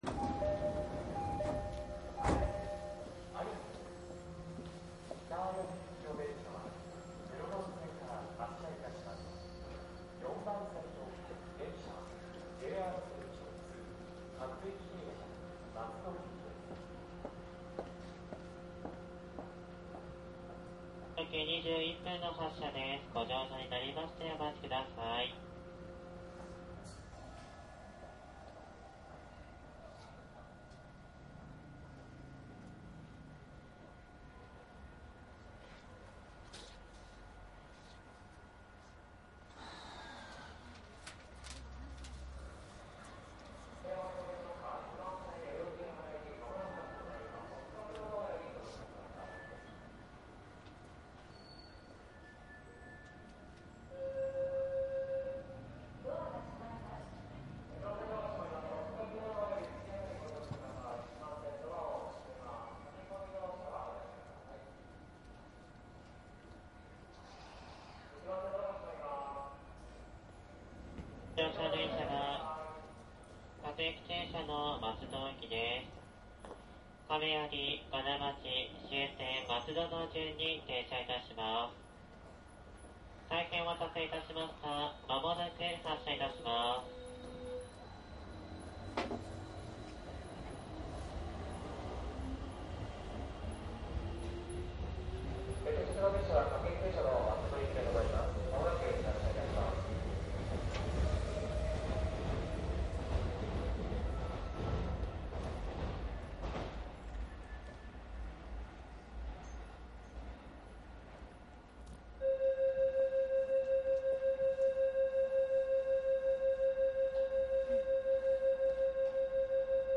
♪JR209系1000番台 千代田線走行音 CDです。
自動放送はありませんので車掌による案内になります。
いずれもマイクECM959です。TCD100の通常SPモードで録音。
実際に乗客が居る車内で録音しています。貸切ではありませんので乗客の会話やが全くないわけではありません。